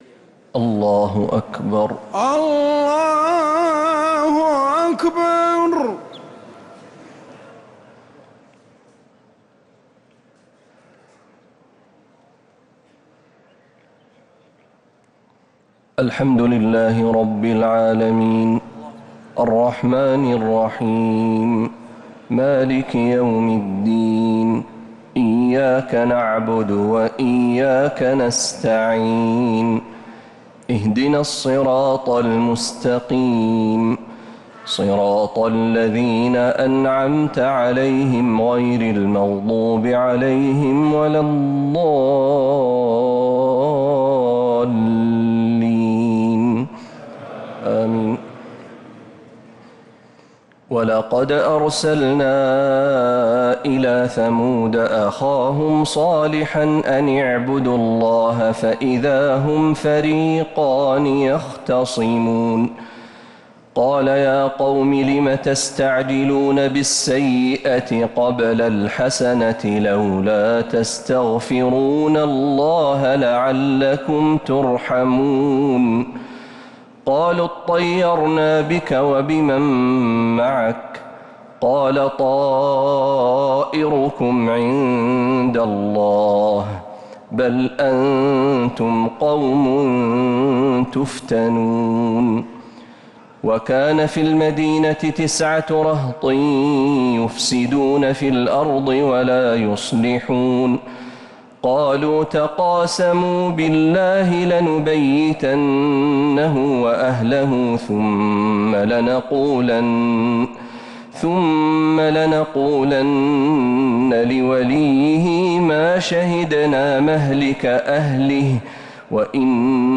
تراويح ليلة 23 رمضان 1447هـ من سورتي النمل (45-93) و القصص (1-13) | taraweeh 23rd night Ramadan 1447H Surah An-Naml and Al-Qasas > تراويح الحرم النبوي عام 1447 🕌 > التراويح - تلاوات الحرمين